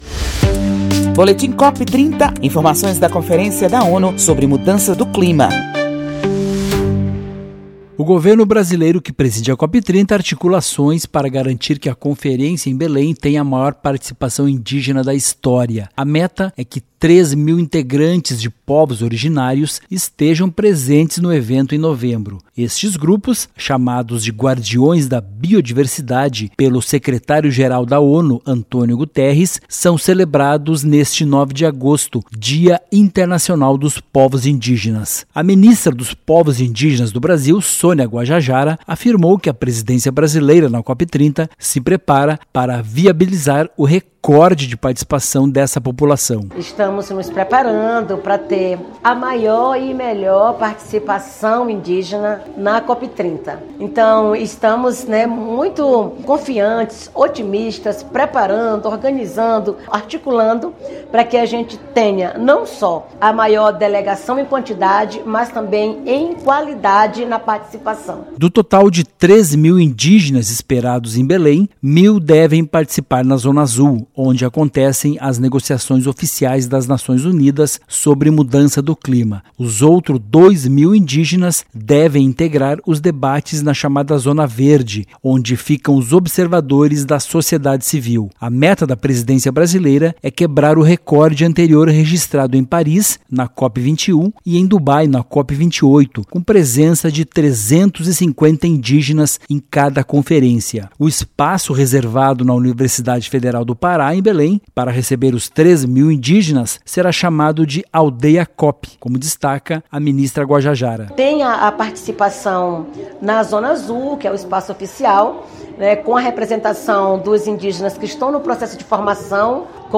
Brasil quer recorde de 3 mil indígenas na COP30, celebrando-os como “guardiões da biodiversidade” neste 9 de agosto, Dia Internacional dos Povos Indígenas. Ouça a reportagem e saiba mais.